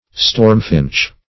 stormfinch - definition of stormfinch - synonyms, pronunciation, spelling from Free Dictionary
stormfinch - definition of stormfinch - synonyms, pronunciation, spelling from Free Dictionary Search Result for " stormfinch" : The Collaborative International Dictionary of English v.0.48: Stormfinch \Storm"finch`\, n. (Zool.)